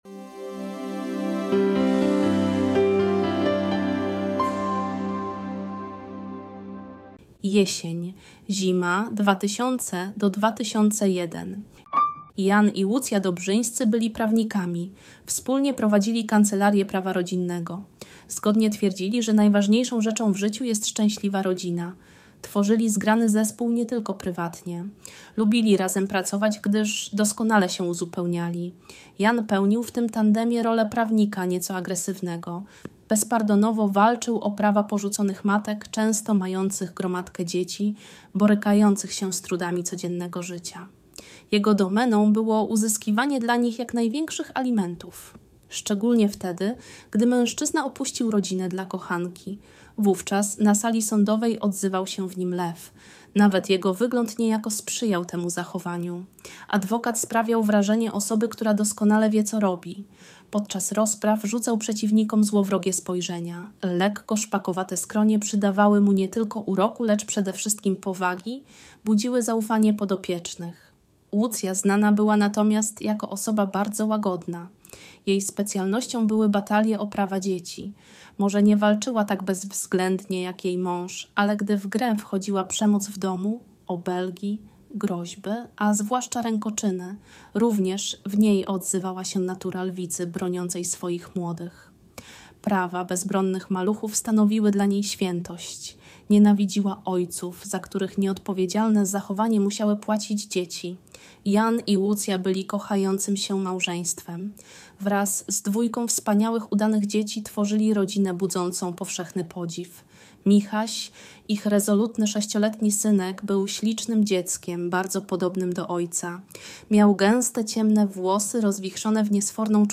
Tajemnica starego kufra – audiobook